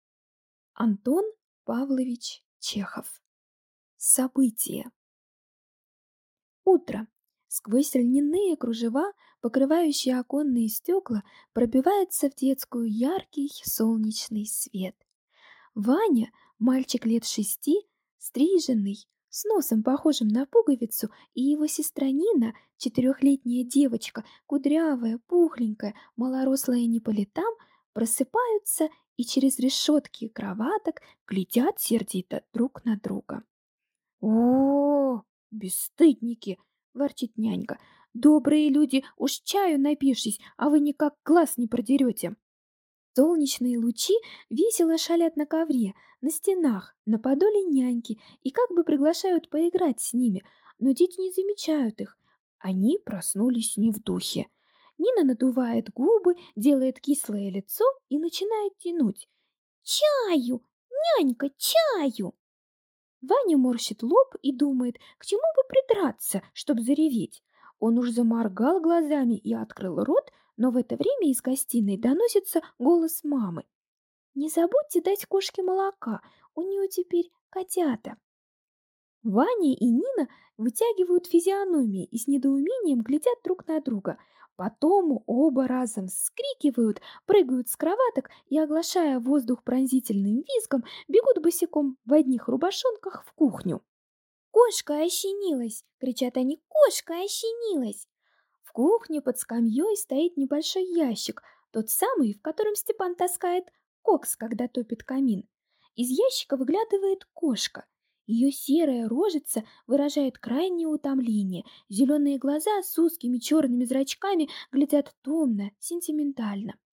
Аудиокнига Событие | Библиотека аудиокниг